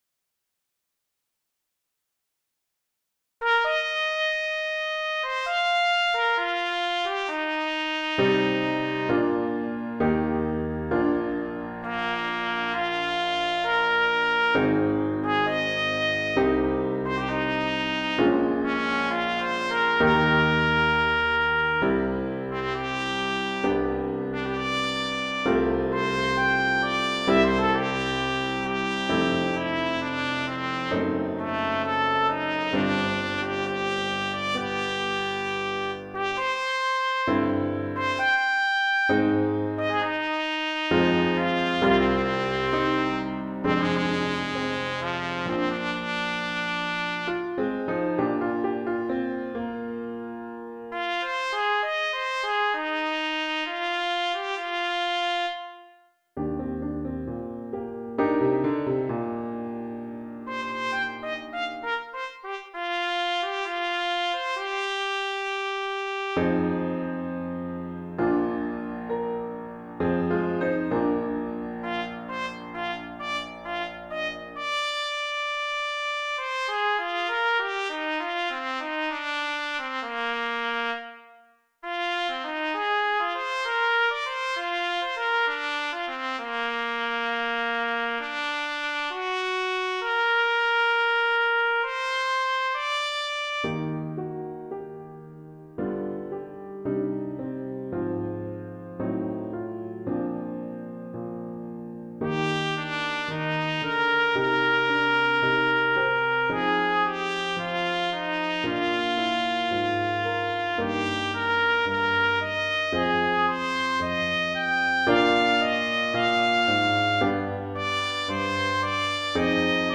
pour trompette ou cornet et piano